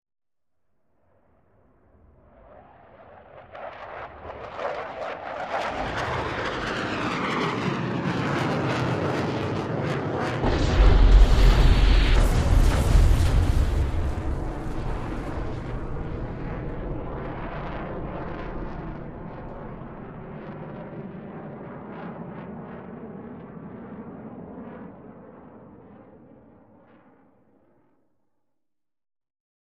Fighter jet drops exploding bombs. Incoming, Explosion War, Explosion Explode, Bomb